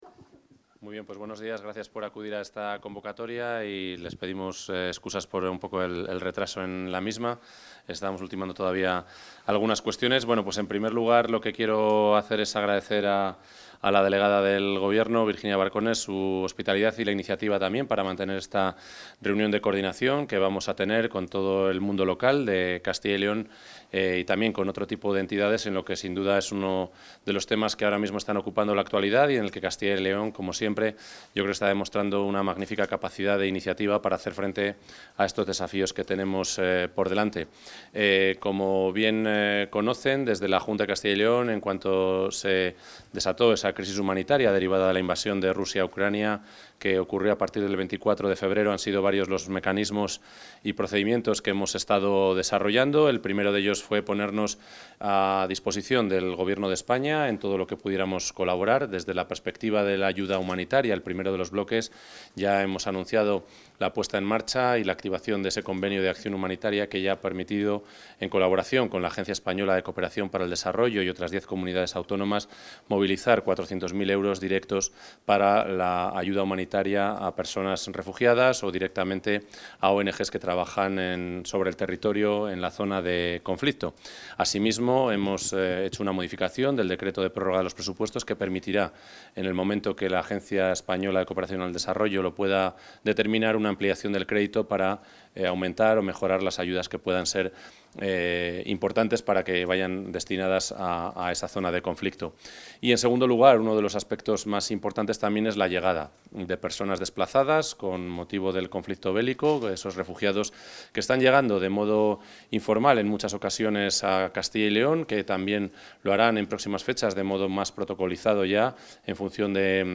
Intervención consejero de la Presidencia.